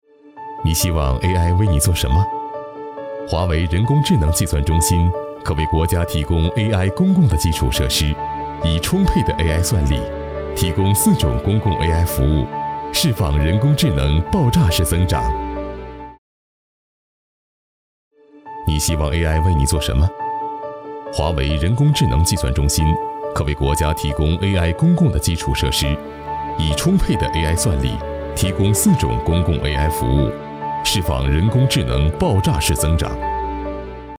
轻松自然 电子科技宣传片配音
大气、高性价比男中音，擅长专题解说配音、宣传片配音、科技感配音、颁奖配音等题材。